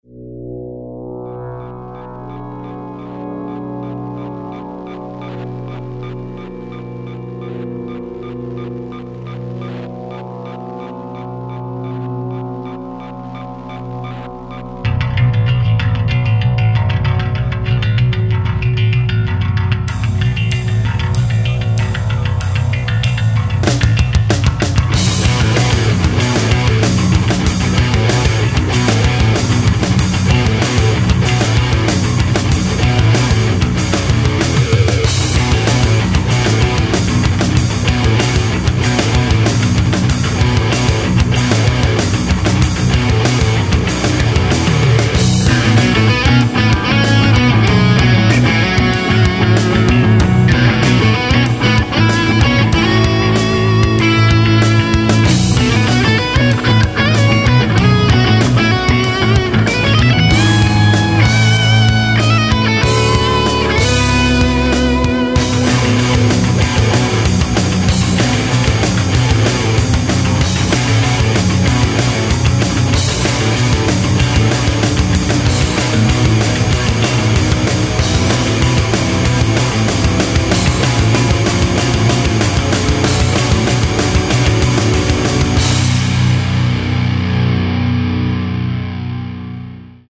pekne, dobry zvuk to ma.  v com si to robil? bicie a tak ..
Je to tranzistor Mashall valvestate 100W komo.
Inak gitara je ziva aj basa a bicie fruity loops.
Fajn ukazky, prijemne sa to pocuva, pekne melodicke motivy.